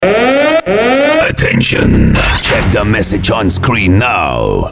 Назад в Голоса Файл 26.mp3 (1) Листать файлы Добавил Это не Adult файл Файл проверен от 25.10.2007, вирусов нет Скачать (55.5кб/05с. /96kbps) Описание: Проверьте сообщение на экране(на англ.)